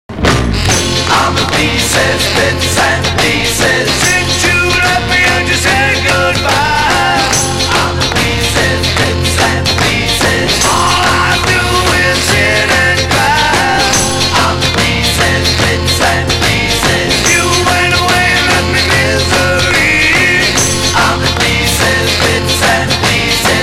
(税込￥6600)   MONO